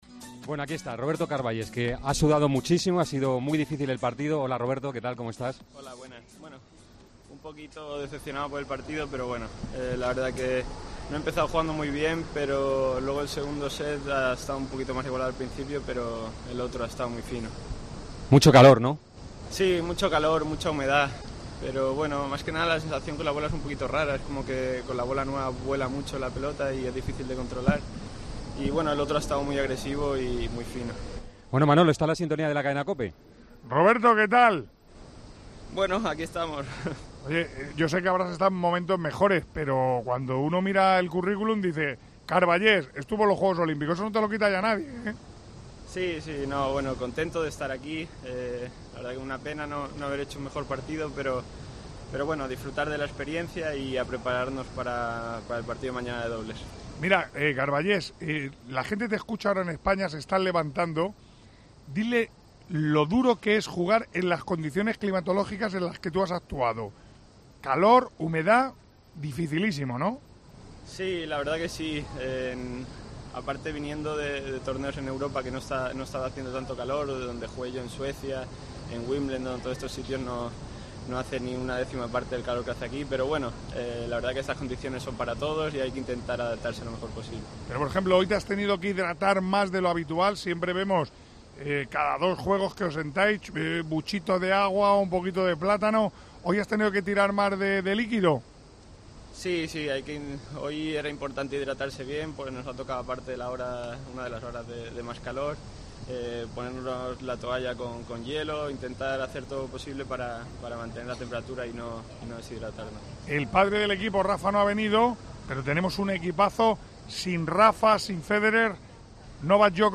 El español se mostró "decepcionado" tras ser derrotado en primera ronda ante Basilashvili en su debut en unos Juegos Olímpicos. Así lo ha contado en la Cadena COPE.